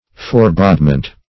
Forebodement \Fore*bode"ment\, n.